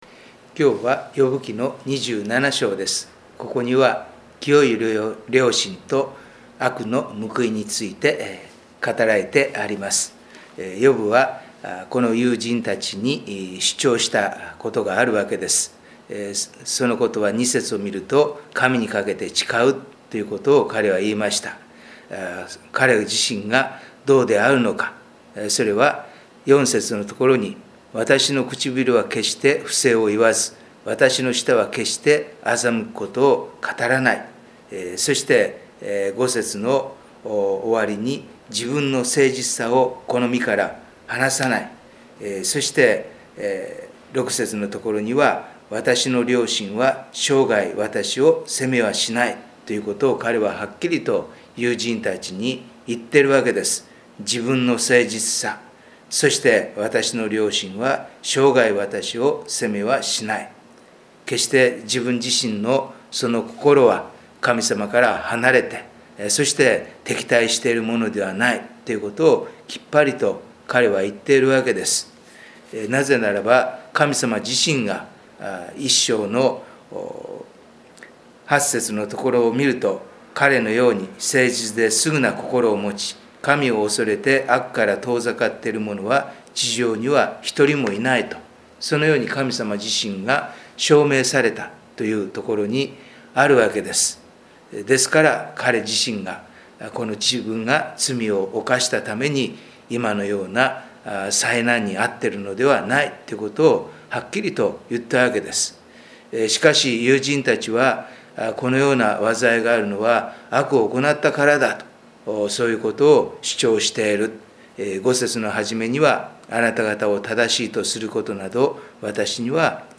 3月のデボーションメッセージ